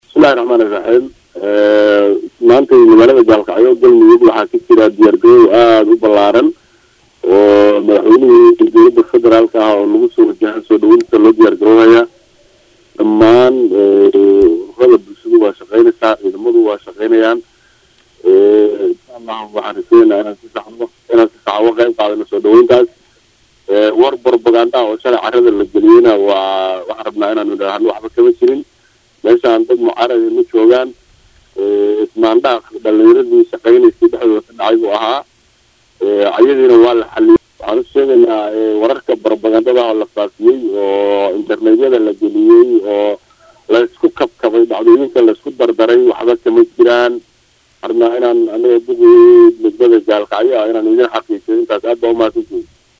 Duqa Magaalada Gaalkacyo ee Xaruunta Gobolka Mudug ee Galmudug Xirsi yuusuf Bare oo la hadlay Radio Muqdisho Codka Jamhuuriyadda Soomaaliya
Wareysi-Gud-Gaalkacyo-Xirsi.mp3